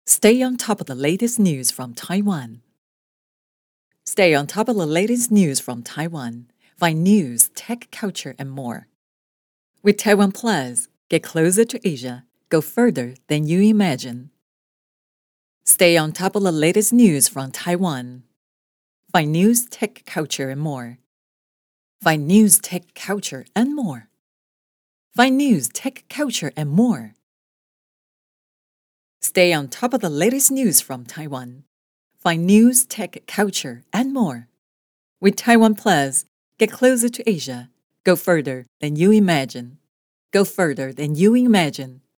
Voix off
- Baryton